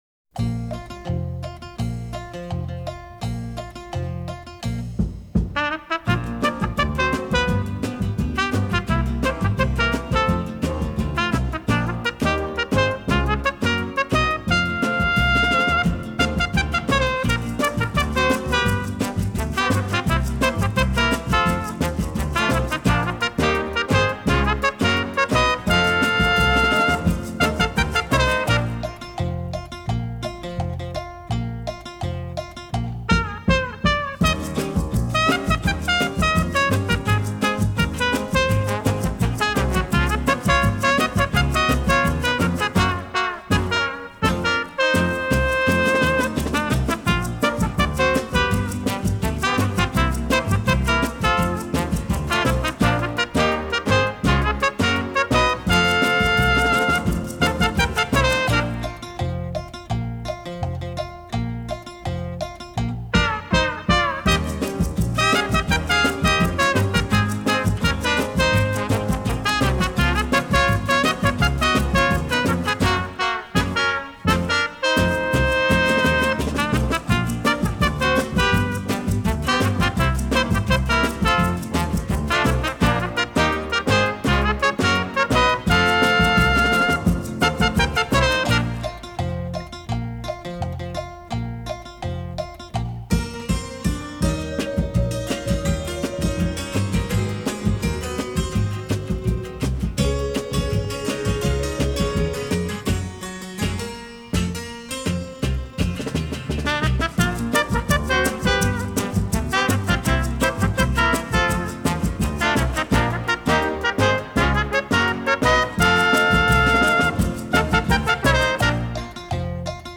Genre:Jazz,Instrumental,Easy listening